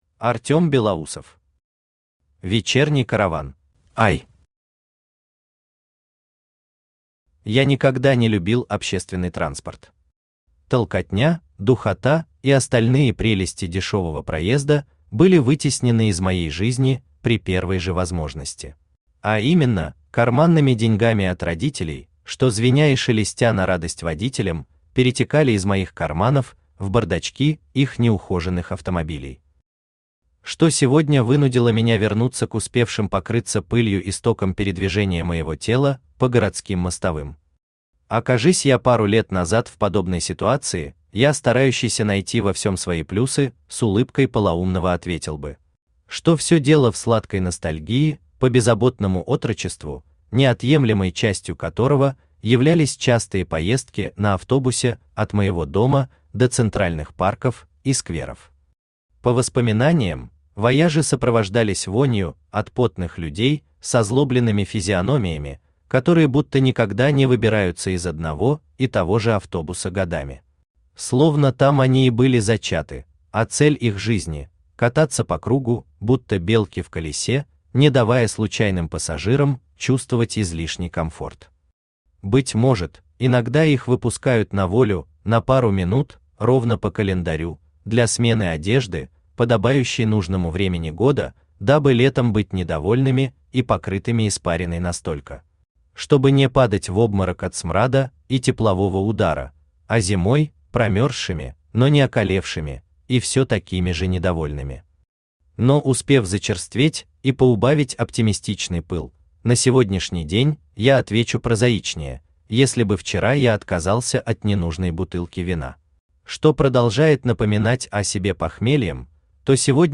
Aудиокнига Вечерний караван Автор Артем Андреевич Белоусов Читает аудиокнигу Авточтец ЛитРес.